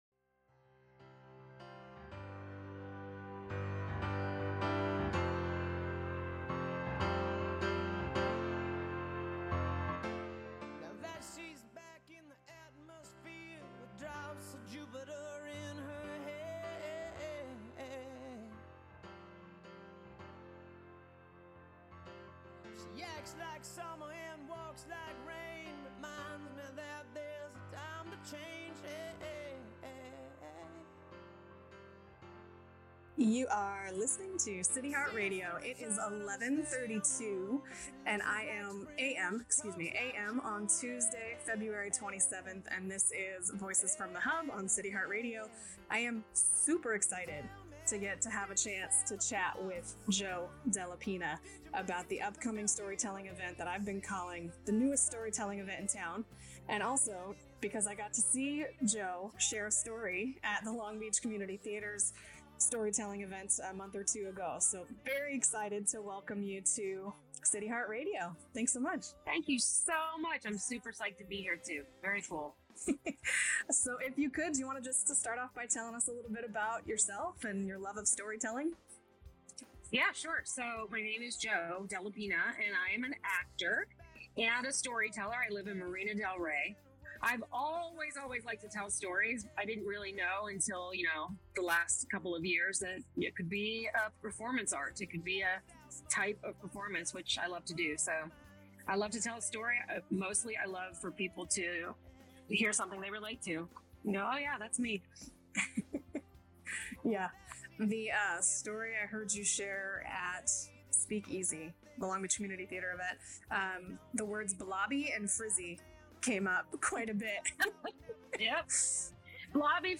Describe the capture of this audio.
This segment of Voices from THE HUB aired live on CityHeART Radio on Tuesday Feb 27 at 11:30am.